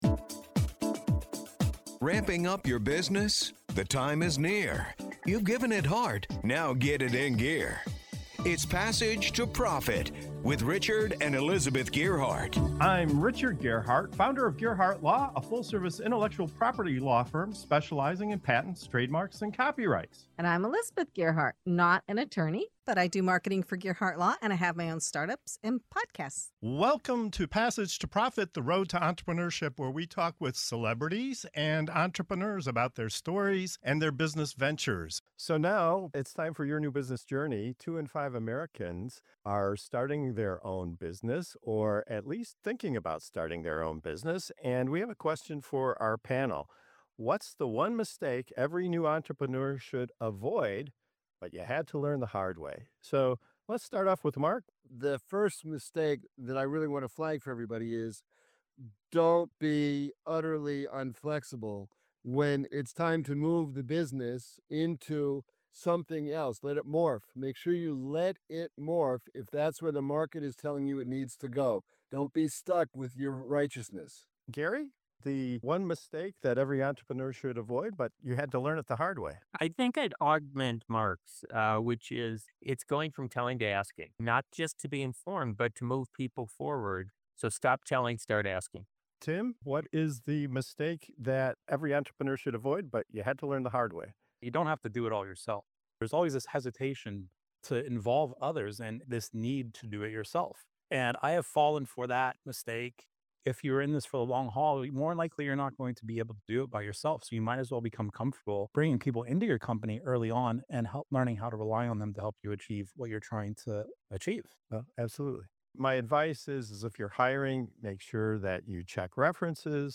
In this segment of "Your New Business Journey" on Passage to Profit Show, our expert panel gets real about the painful lessons every new entrepreneur wishes they knew sooner.
You’ll also hear a candid warning about hiring pitfalls and a surprising take on how social media is sabotaging focus and founders. If you’re starting a business—or even thinking about it—this fast-paced conversation is packed with hard-earned wisdom to help you launch smarter.